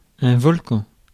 Ääntäminen
US RP : IPA : /vɒl.ˈkeɪ.nəʊ/ GAm: IPA : /vɑl.ˈkeɪ.noʊ/